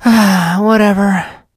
sandy_hurt_vo_05.ogg